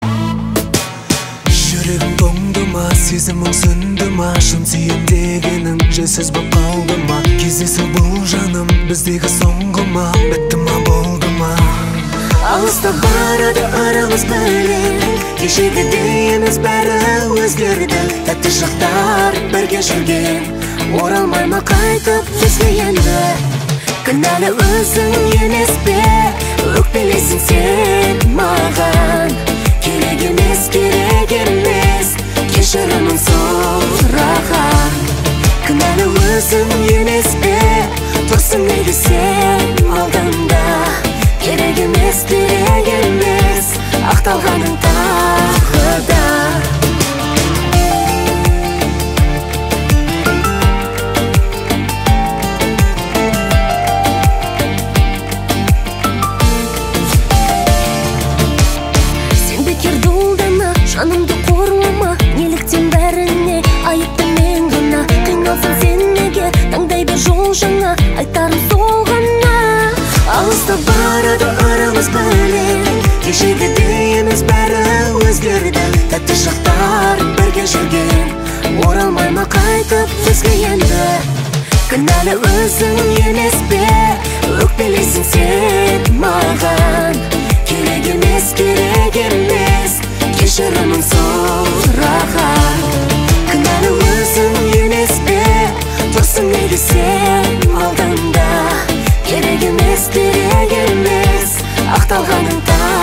• Качество: 256, Stereo
поп
дуэт
Казахская романтичная песня